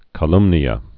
(kə-lŭmnē-ə)